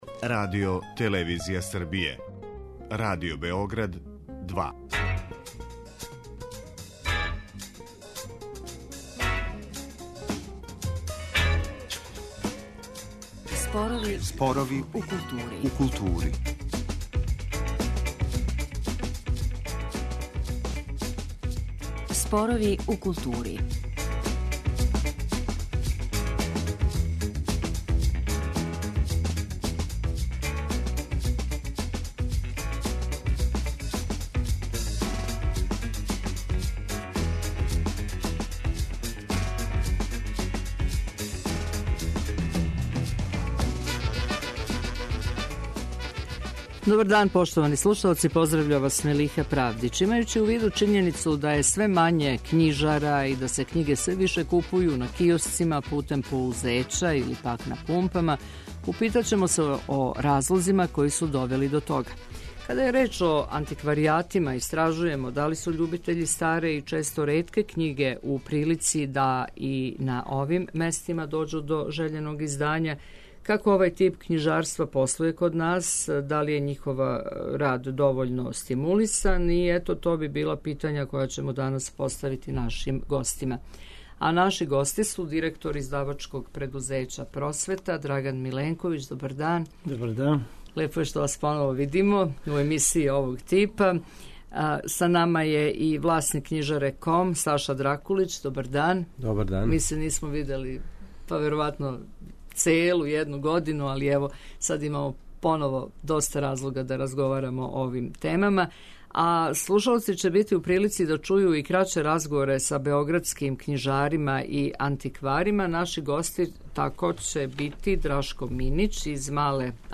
а слушаоци ће бити у прилици да чују и краће разговоре са београдским књижарима и антикварима.